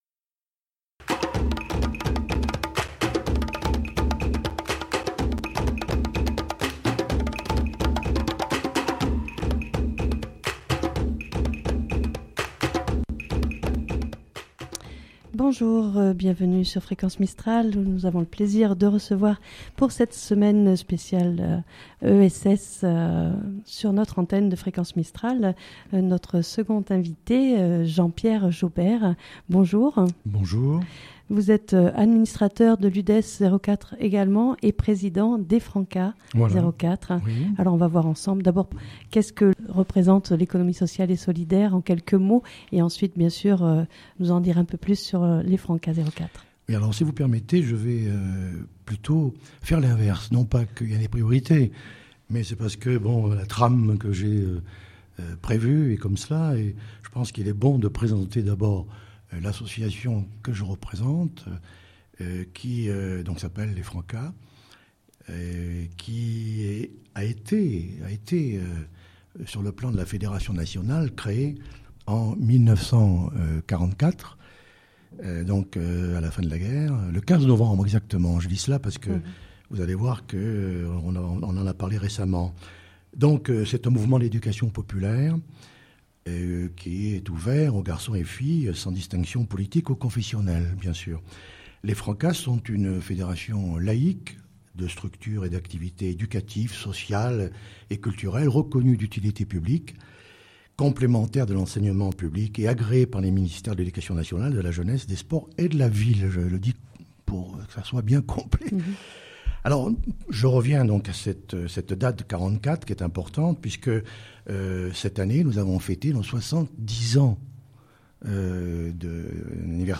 Novembre, c'est le Mois de l'Économie Sociale et Solidaire ! Fréquence Mistral reçoit chaque jour en direct du studio de Digne, des acteurs de l'Economie sociale et solidaire.